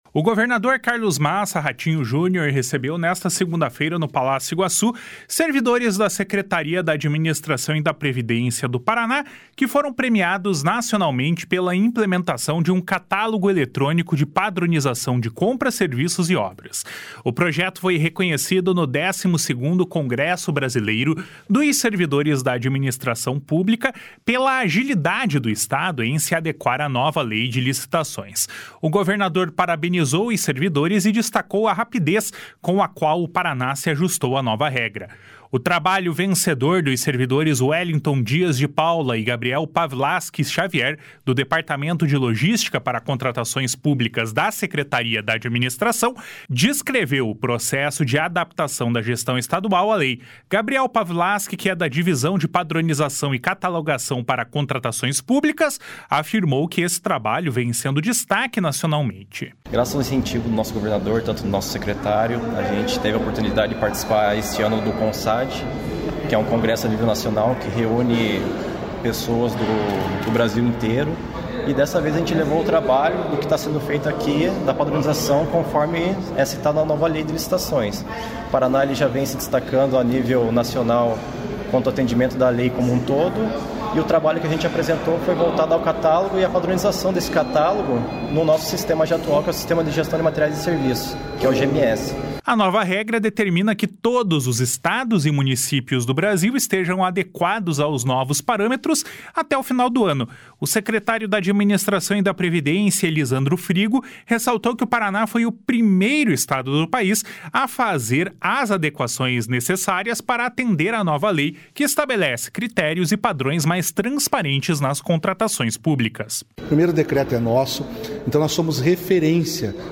O secretário da Administração e da Previdência, Elisandro Frigo, ressaltou que o Paraná foi o primeiro estado do País a fazer as adequações necessárias para atender à Nova Lei de Licitações, que estabelece critérios e padrões mais transparentes nas contratações públicas. // SONORA ELISANDRO FRIGO //